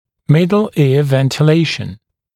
[‘mɪdl ɪə ˌventɪ’leɪʃn] [‘мидл иа ˌвэнти’лэйшн] вентиляция среднего уха